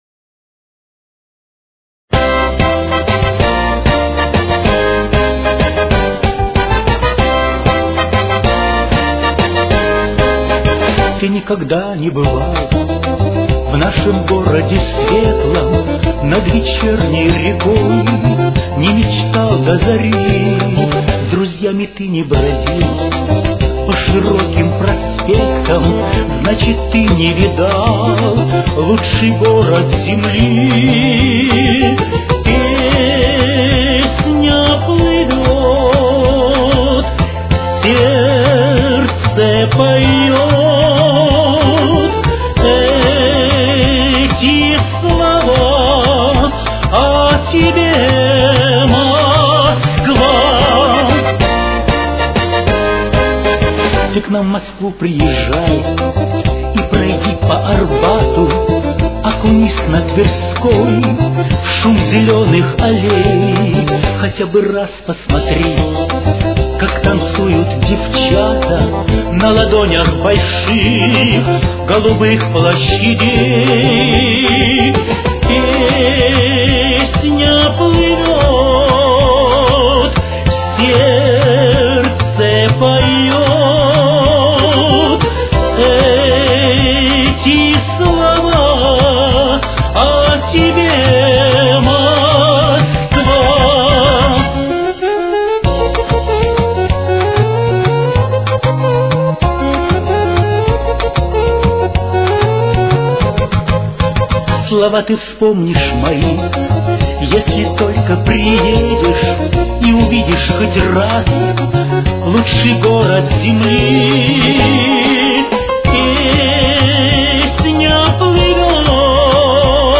Темп: 194.